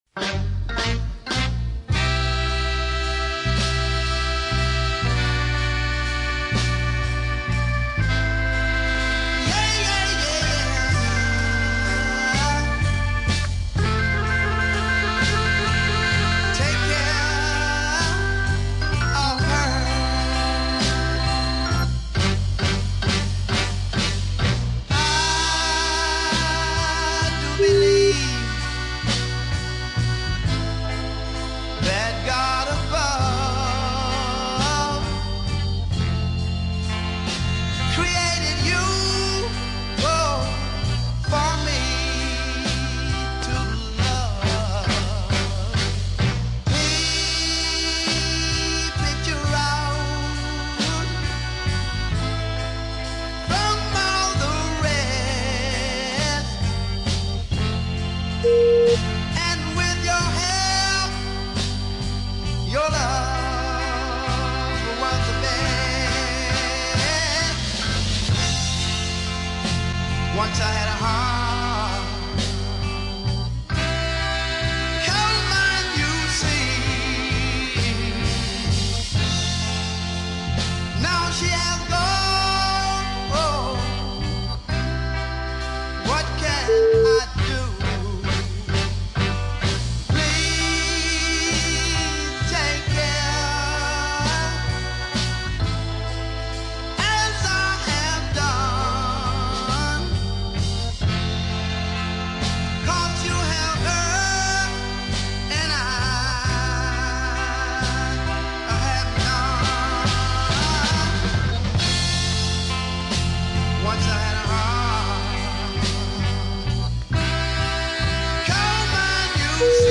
well organised horns
tasteful organ fills